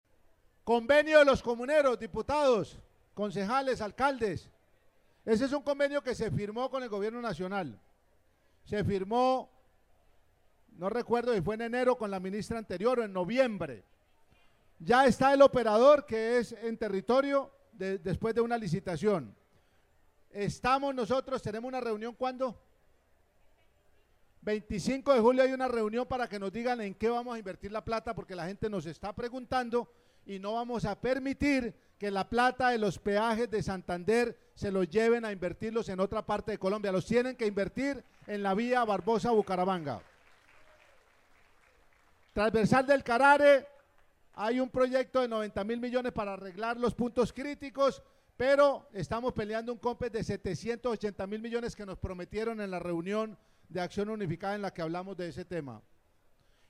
Juvenal Díaz, gobernador de Santander
El gobernador de Santander, Juvenal Díaz, en su intervención en el marco de la rendición de cuentas, llevada a cabo en las últimas horas en el parque Cristo Rey de Bucaramanga, aseguró que no permitirá que los recursos recaudados en los peajes de la región sean destinados para financiar obras en otras zonas del país.